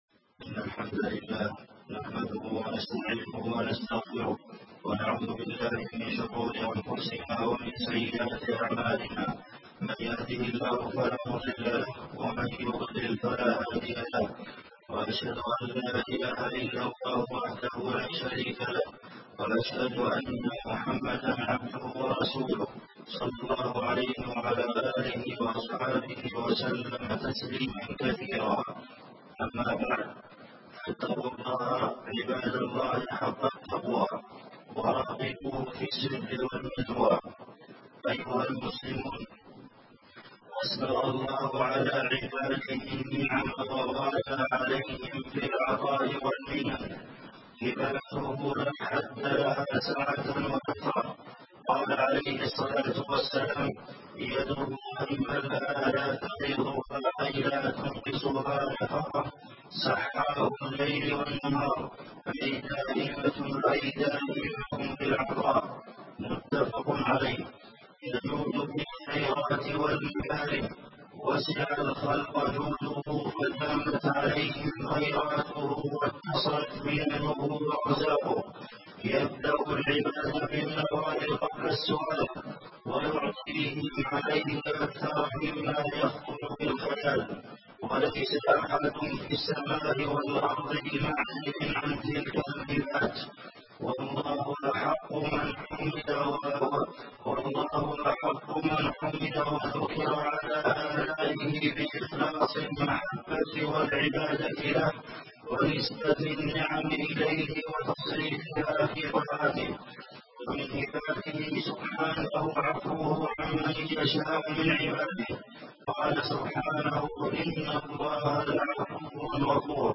تاريخ النشر ٢١ رمضان ١٤٣٨ هـ المكان: المسجد النبوي الشيخ: فضيلة الشيخ د. عبدالمحسن بن محمد القاسم فضيلة الشيخ د. عبدالمحسن بن محمد القاسم التسابق إلى الطاعات في رمضان